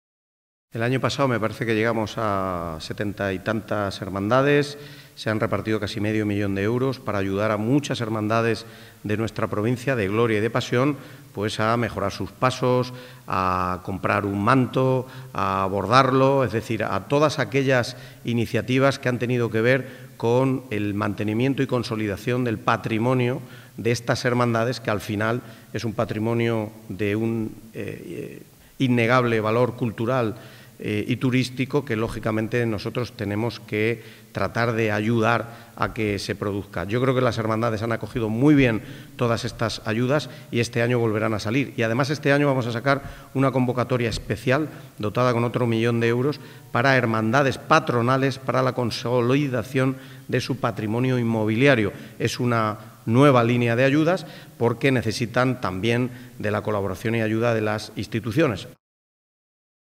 • Miguel Ángel Valverde anuncia la nueva línea de ayudas durante una tertulia radiofónica y avanza la próxima declaración de Interés Turístico Internacional para la Ruta de la Pasión Calatrava
tertulia_semana_santa_2025_de_onda_cero_corte_pte_02.mp3